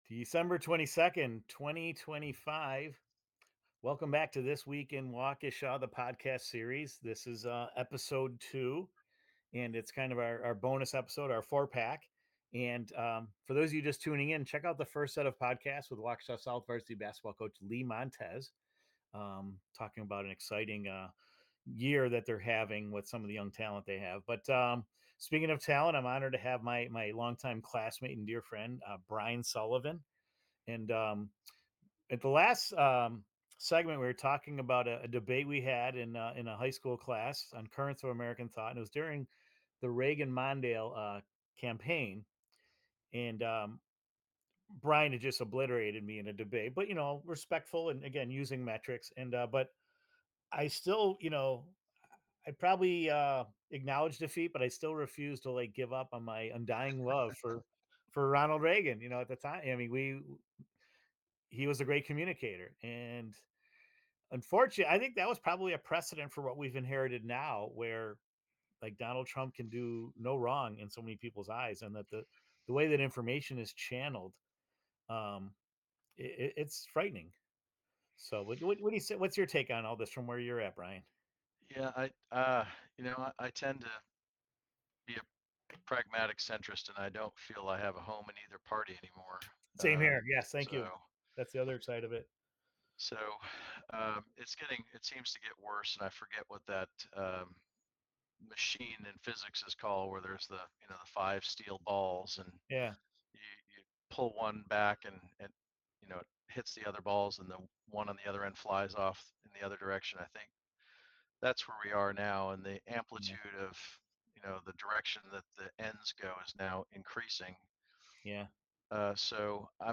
Broadcasting from the office of former Alderman Don Browne in Banting Park, the conversation explores metallurgical coal and steelmaking, Great Lakes industrial history, global supply chains, and the realities of operating an international business amid political uncertainty.